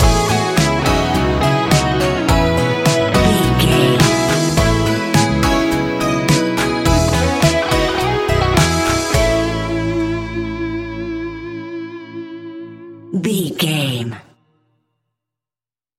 Ionian/Major
ambient
electronic
chill out
downtempo
pads